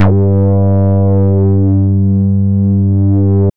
Index of /90_sSampleCDs/Trance_Explosion_Vol1/Instrument Multi-samples/Wasp Bass 2
G2_WaspBass2.wav